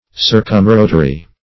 Circumrotary \Cir`cum*ro"tary\